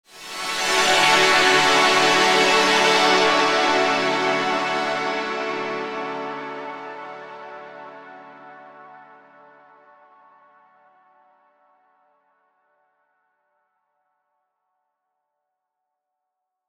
Chords_Dmaj_02.wav